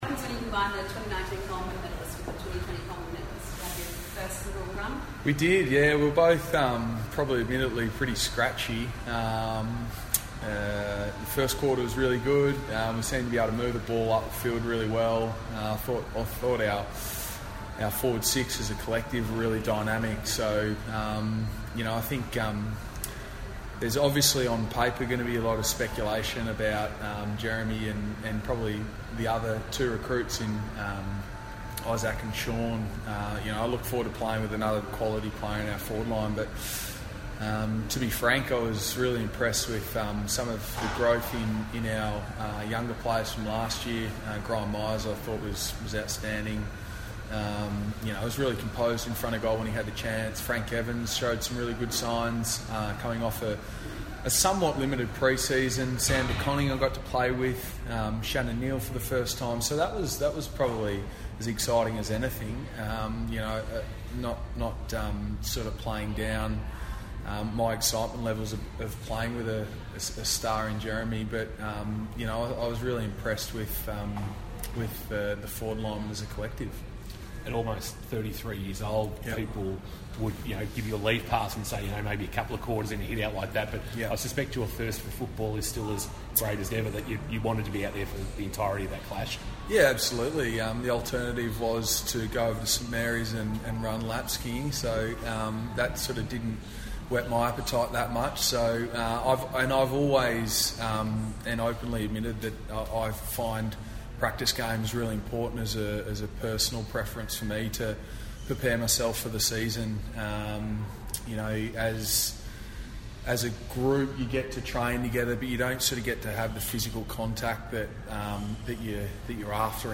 Geelong's Tom Hawkins faced the media before Saturday's AAMI Community Series match-up with Essendon.